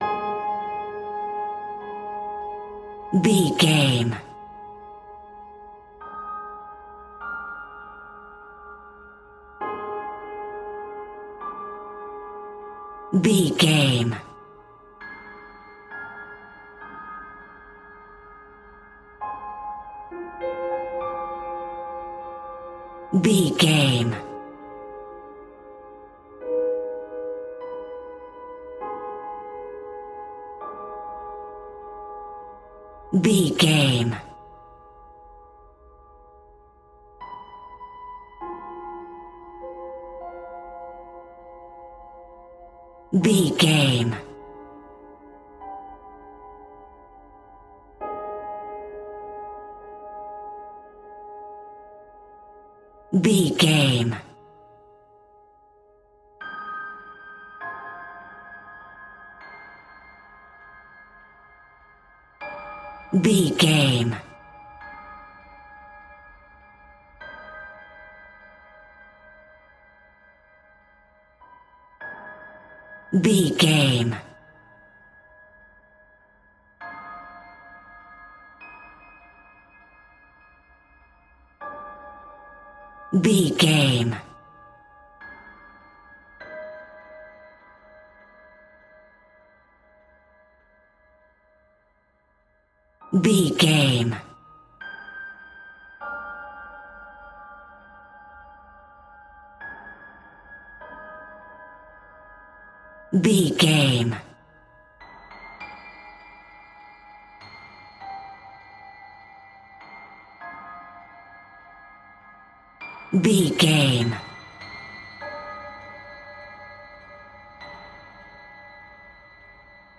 A great piece of royalty free music
In-crescendo
Thriller
Aeolian/Minor
scary
tension
ominous
suspense
haunting
eerie
creepy
instrumentals
horror music
horror piano